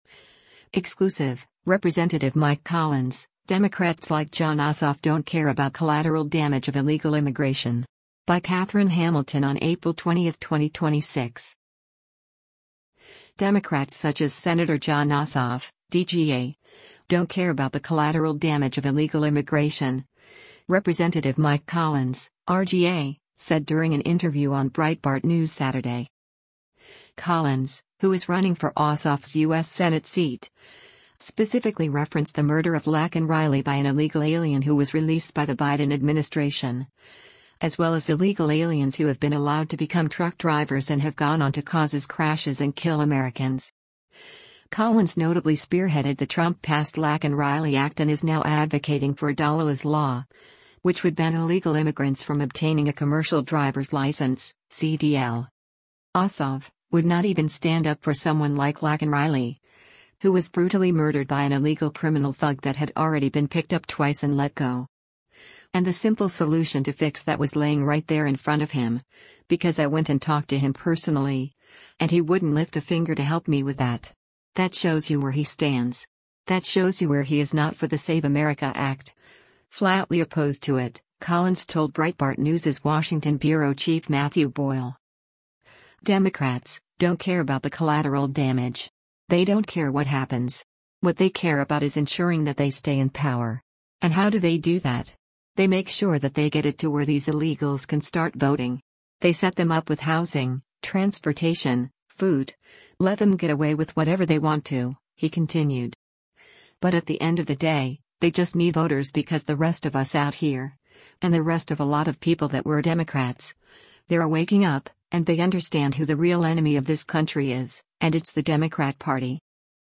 Democrats such as Sen. Jon Ossoff (D-GA) “don’t care about the collateral damage” of illegal immigration, Rep. Mike Collins (R-GA) said during an interview on Breitbart News Saturday